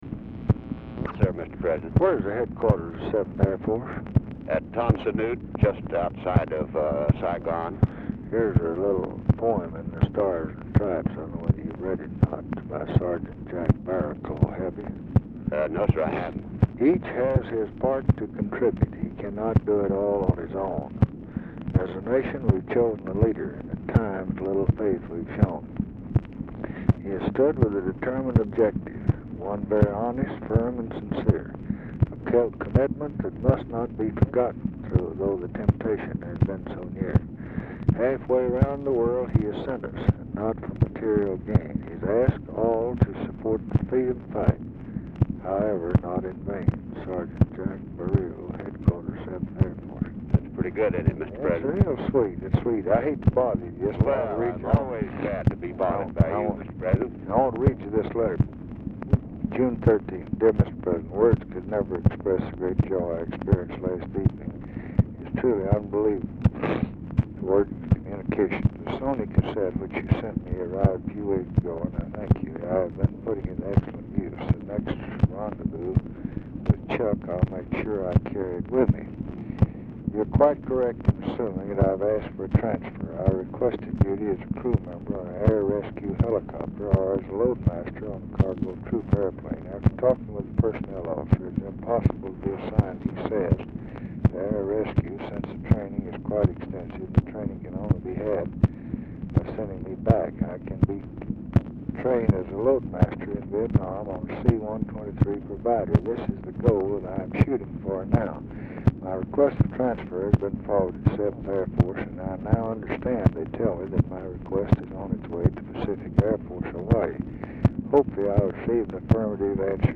Telephone conversation # 13123, sound recording, LBJ and JOHN MCCONNELL, 6/20/1968, 4:44PM | Discover LBJ
Format Dictation belt
Location Of Speaker 1 Mansion, White House, Washington, DC